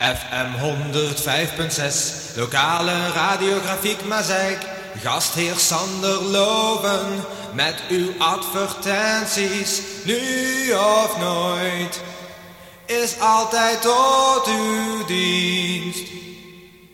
Jingle a-capella lang